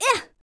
throw_v.wav